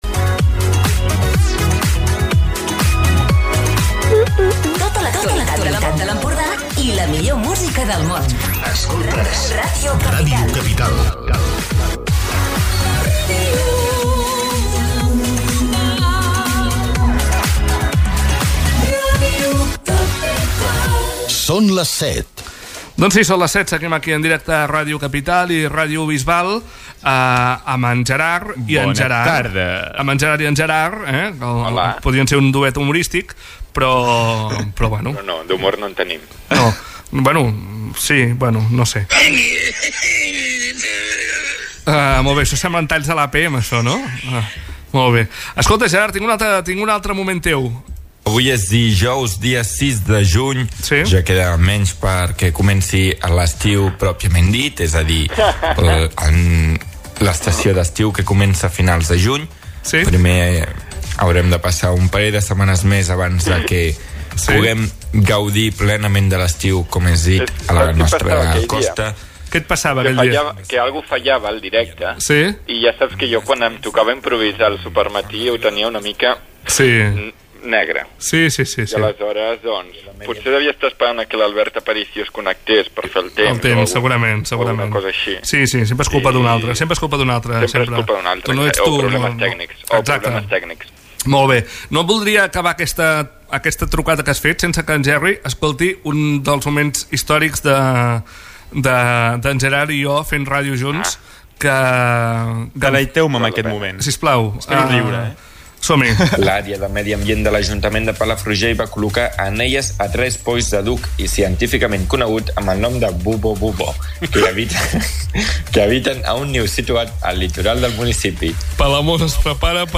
Recupera el programa més interactiu de Ràdio Capital
de les trucades dels oients!